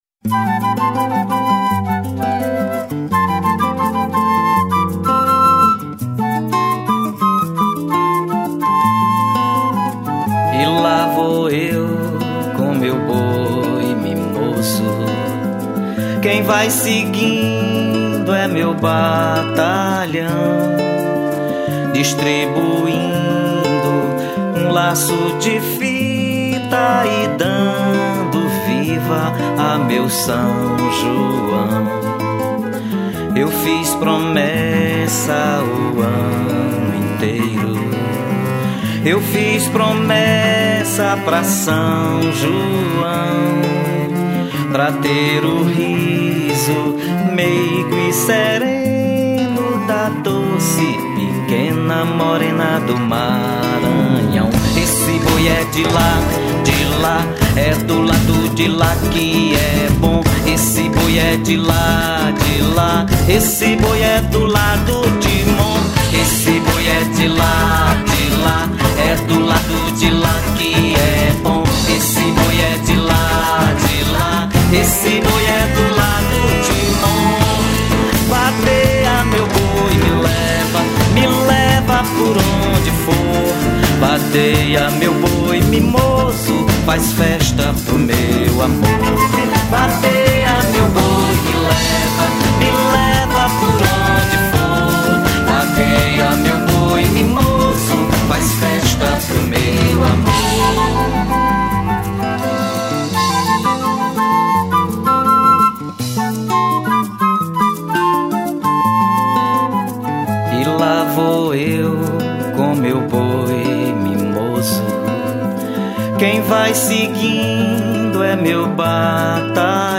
03:59:00   Boi Bumbá